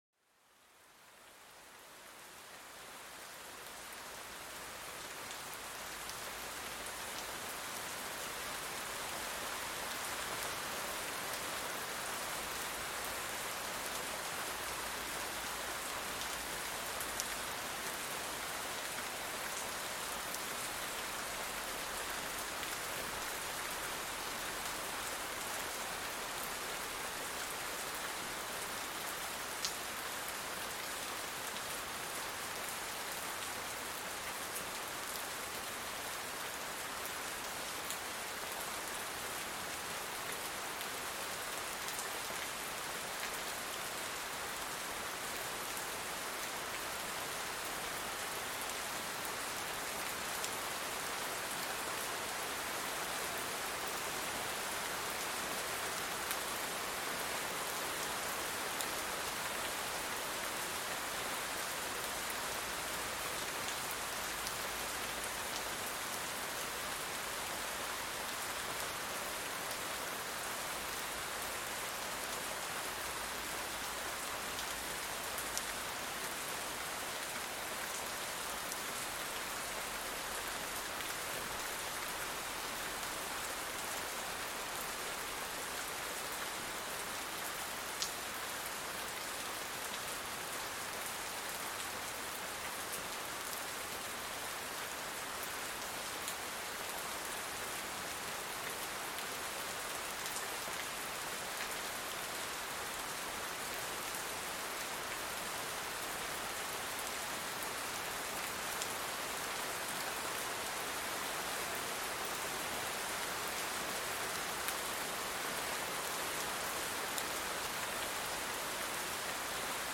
Pluie forte pour une relaxation profonde et apaisante
Plongez dans le son apaisant d'une pluie forte, où chaque goutte martèle le sol, créant une symphonie naturelle. Ces sons rythmiques vous aident à calmer votre esprit et à relâcher la tension accumulée.